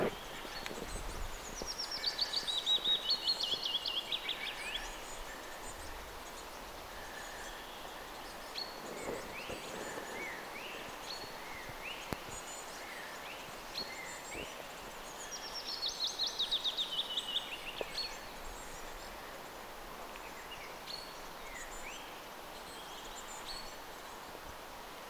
kesän ensimmäinen sinitiaispesue
eka_sinitiaispesue_poikasten_aantelya.mp3